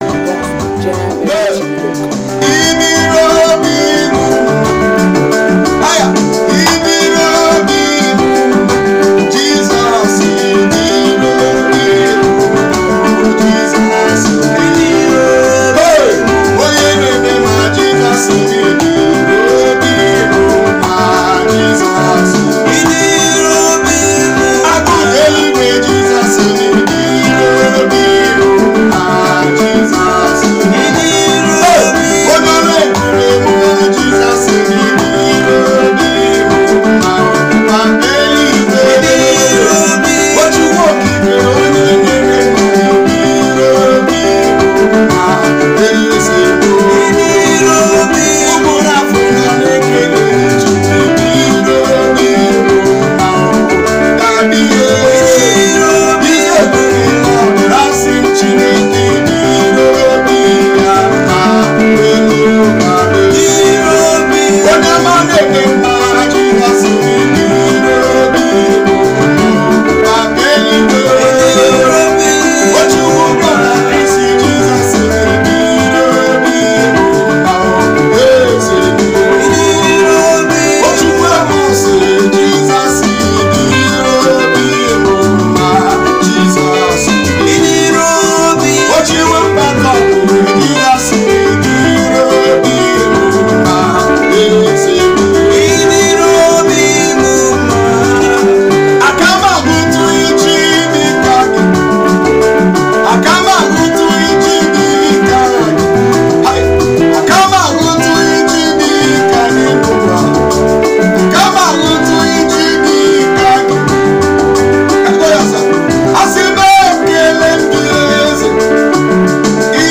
is a live recording sound
a Nigeria gospel singer